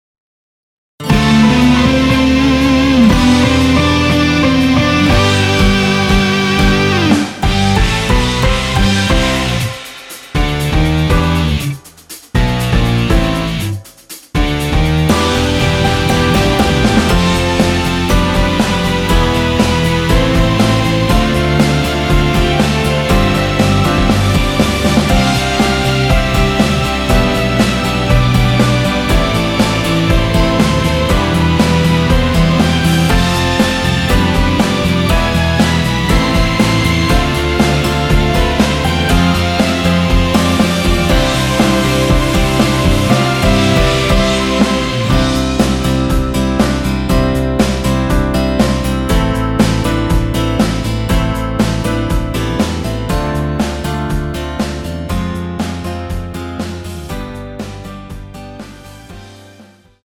앞부분30초, 뒷부분30초씩 편집해서 올려 드리고 있습니다.
중간에 음이 끈어지고 다시 나오는 이유는
축가 MR